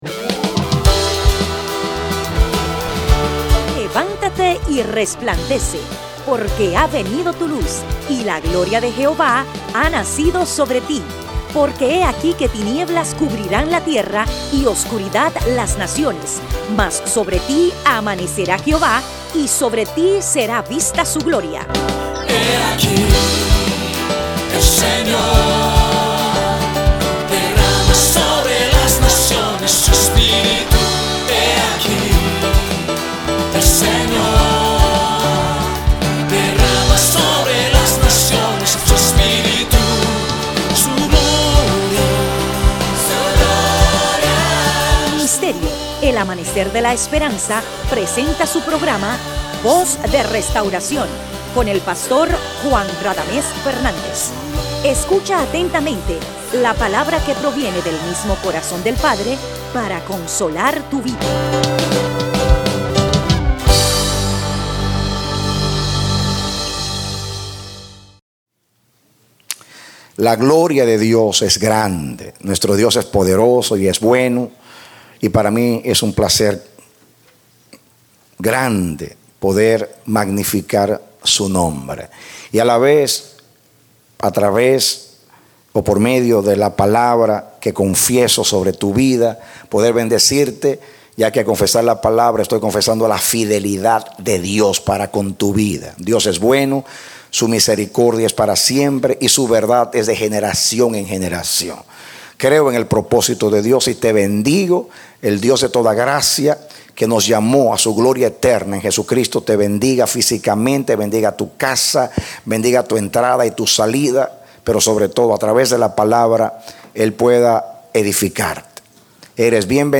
Serie de 4 Predicado Domingo Abril 07, 2013